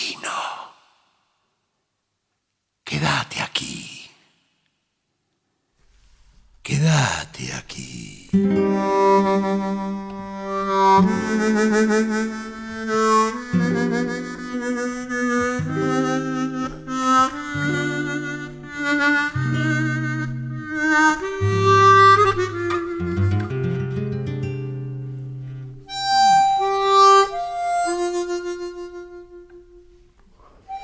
voix
harmonica
guitare